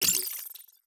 Robotic Game Notification.wav